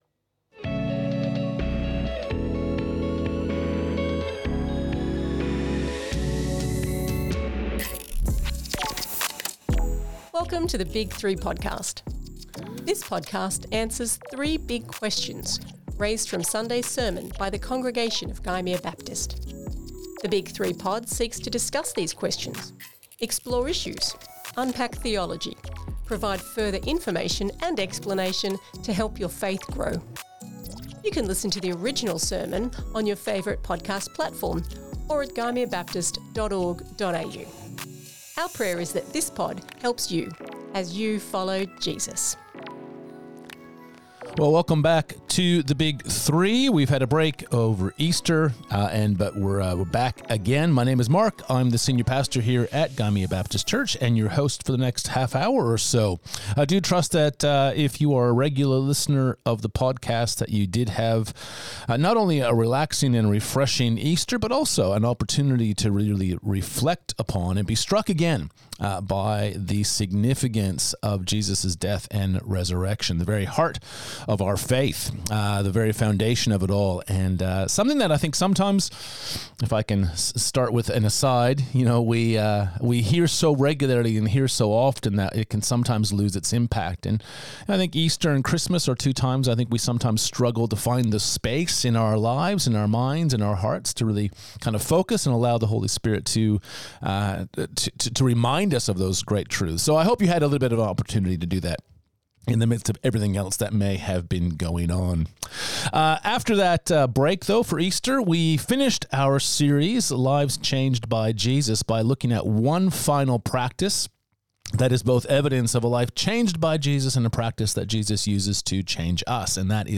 Lives Changed By Jesus Current Sermon The Big 3